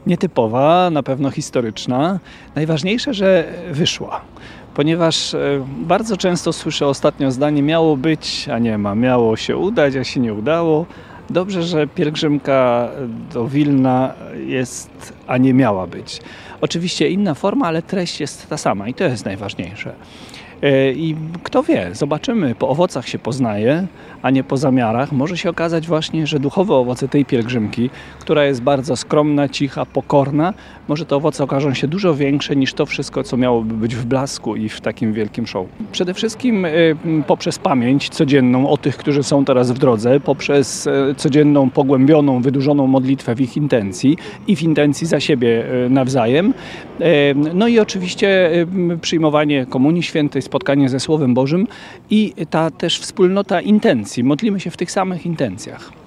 biskup-1.mp3